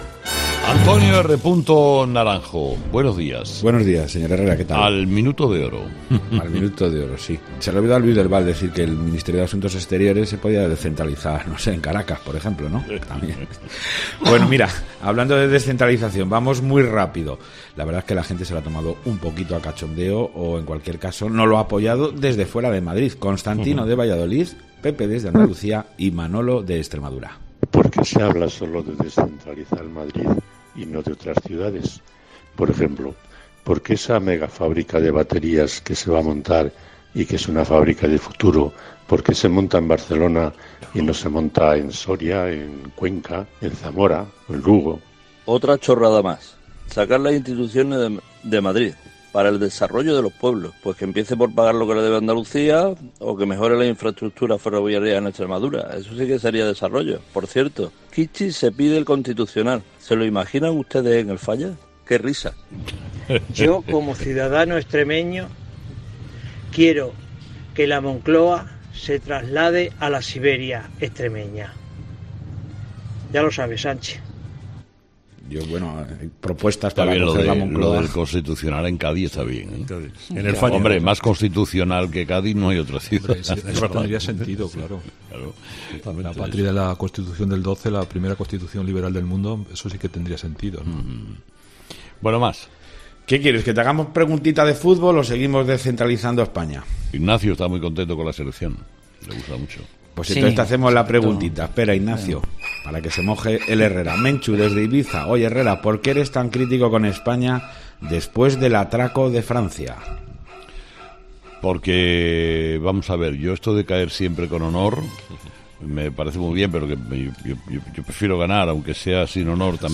La tertulia de los oyentes